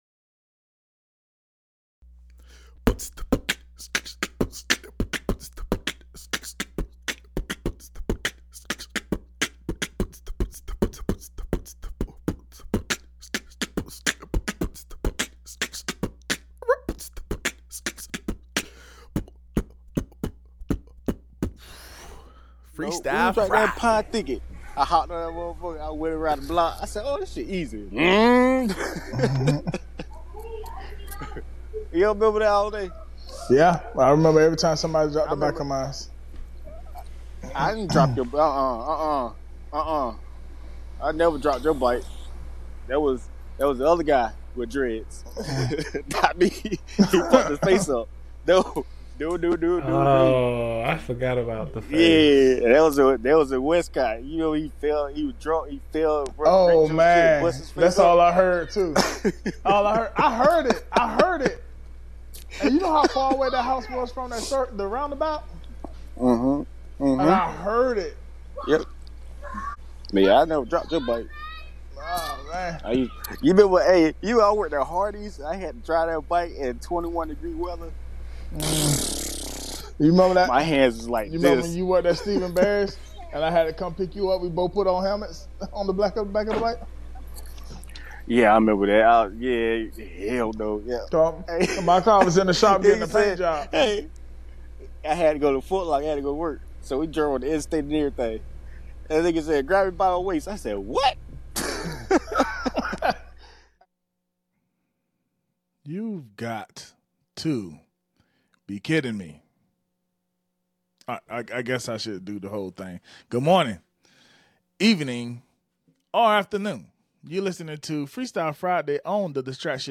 Freestyle Friday – Mike Tyson is Doing What? (Delayed Audio)